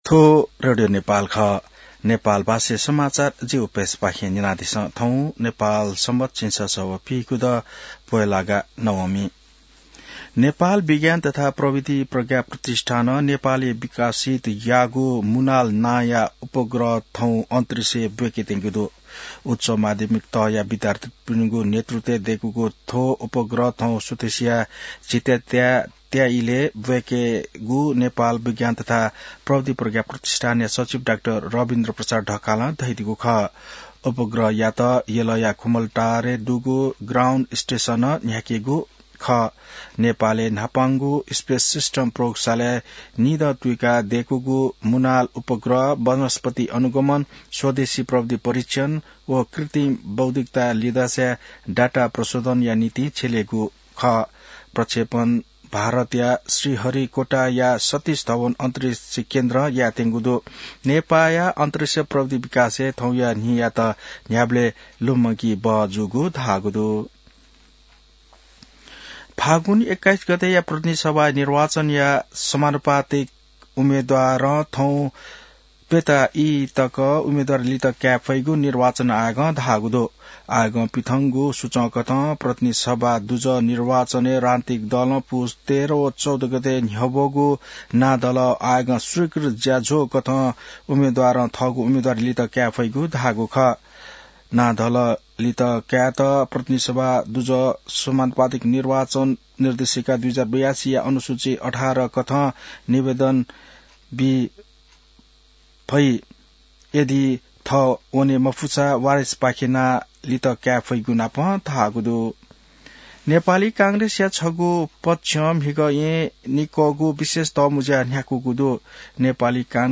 नेपाल भाषामा समाचार : २८ पुष , २०८२